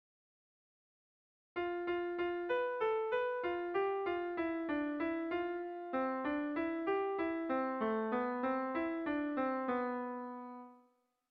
Erlijiozkoa
Lauko txikia (hg) / Bi puntuko txikia (ip)
AB